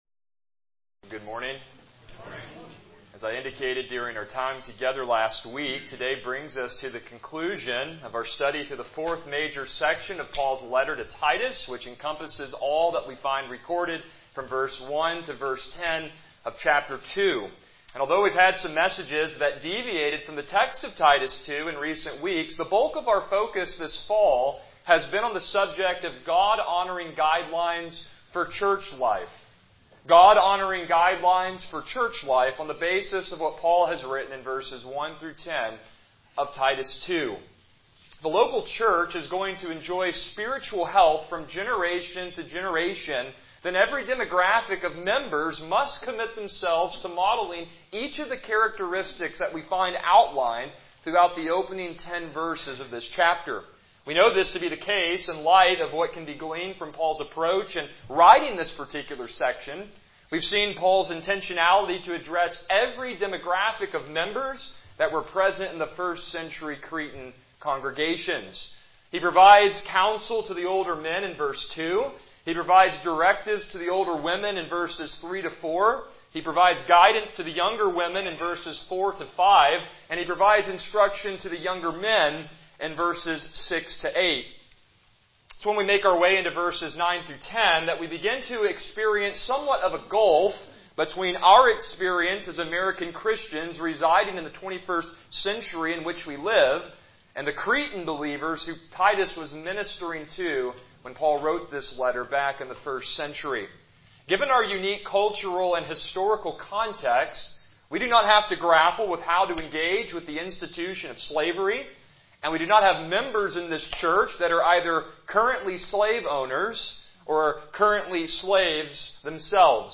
Passage: Titus 2:9-10 Service Type: Morning Worship